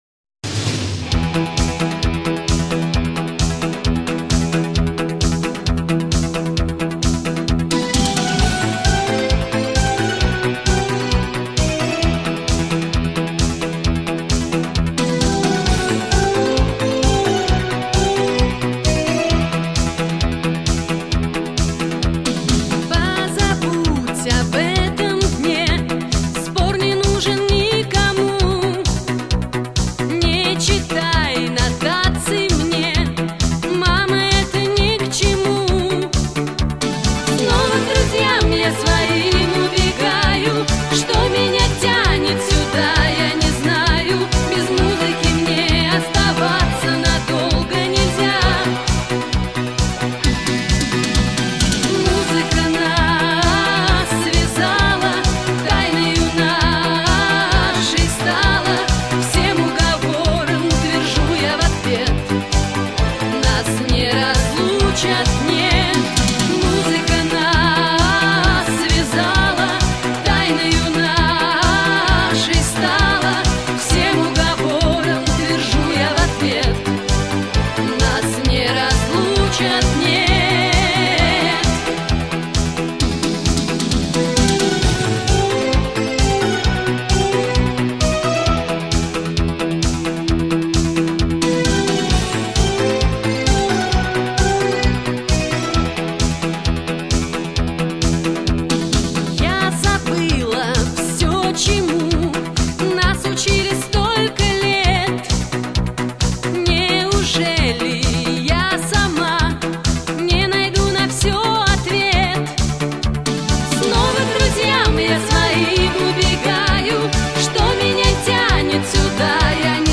в клубной обработке
музыка 80х в современной обработке